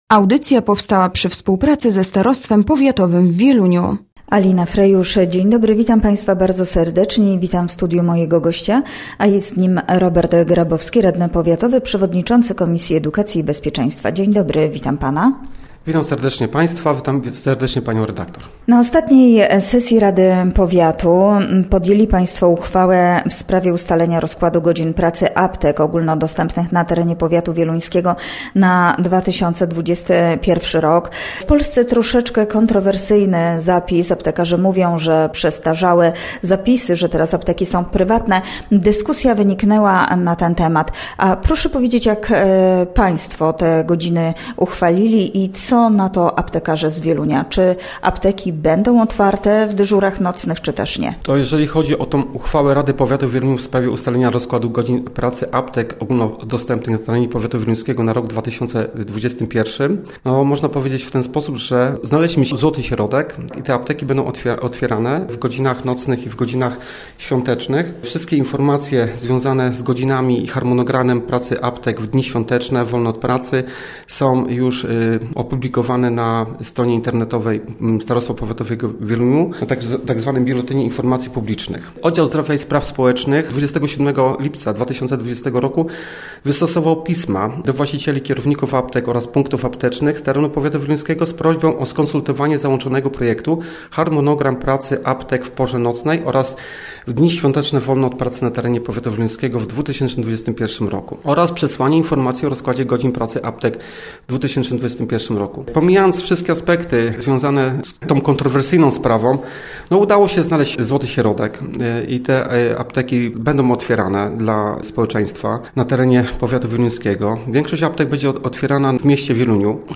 Gościem Radia ZW był Robert Grabowski, radny powiatowy